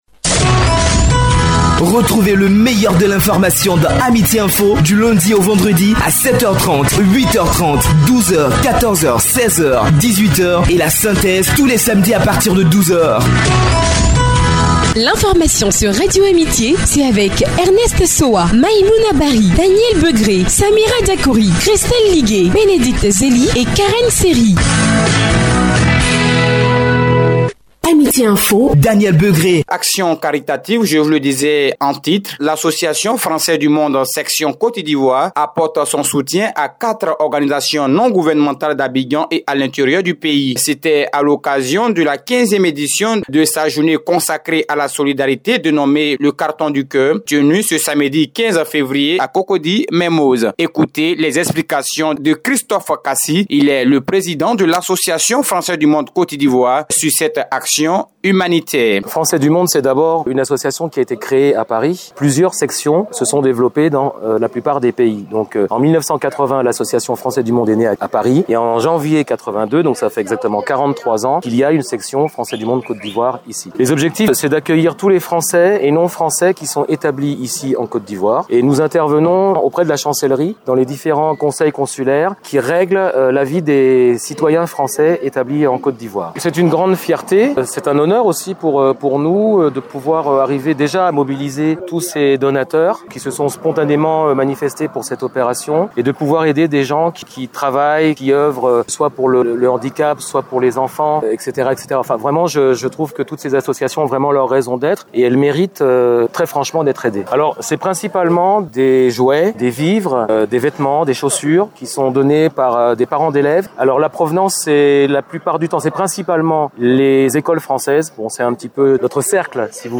Retrouver le sujet traitant de notre remise des cartons du cœur diffusé sur Radio Amitié Yopougon.